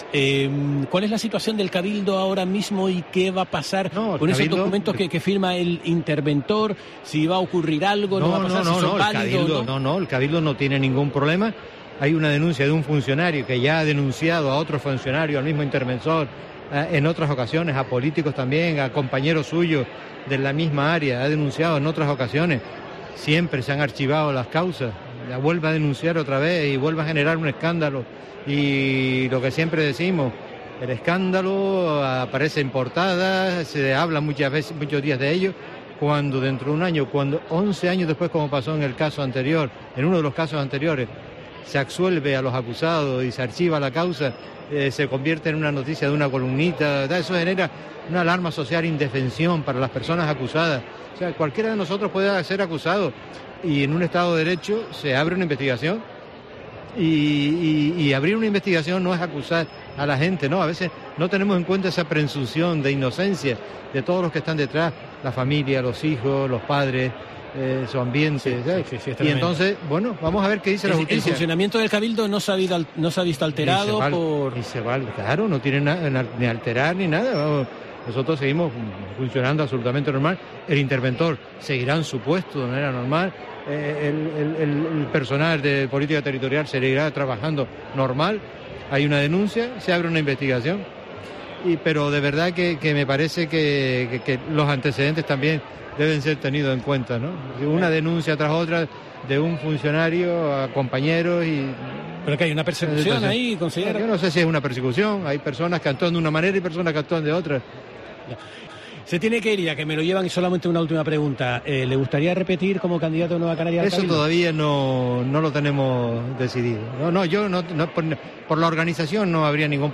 Antonio Morales, presidente del Cabildo insular de Gran Canaria
¿Hay una persecución?, le preguntábamos en COPE Gran Canaria, a lo que respondía simplemente “no lo sé, hay personas que actúan de una manera y hay personas que actúan de otra”.